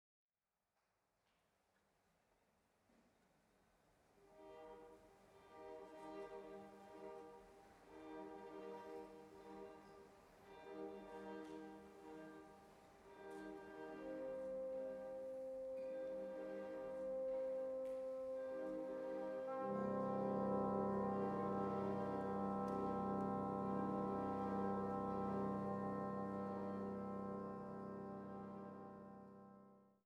Tondichtung für großes Orchester